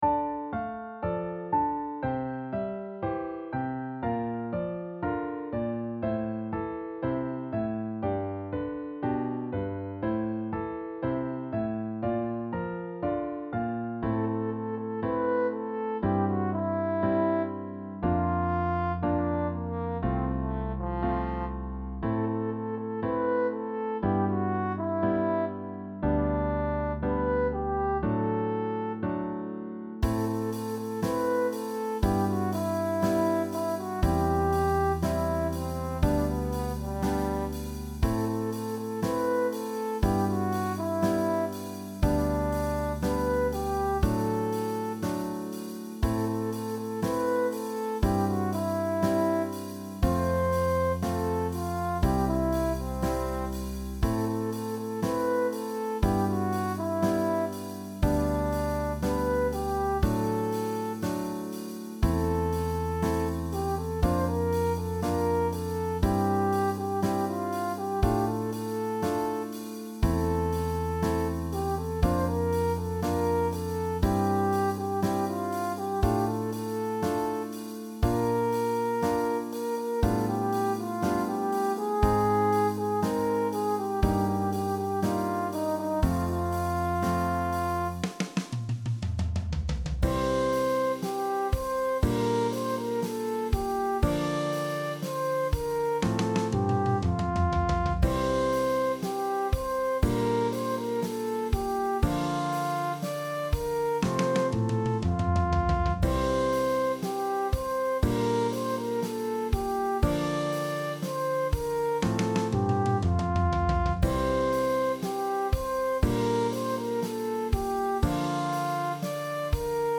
AcousticBallad
순수한 포크 송입니다. 이 곡은 자주 변조됩니다.